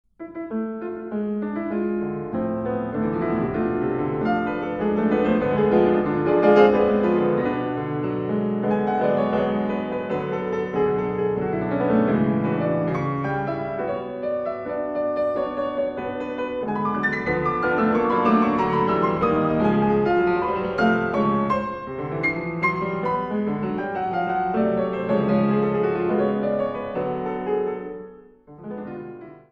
Neue Musik
Sololiteratur
Klavier (1)